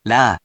If you press the 「▶」button on the virtual sound player, QUIZBO™ will read the random hiragana to you.
In romaji, 「ら」 is transliterated as「ra」which sounds sort of like 「lahh」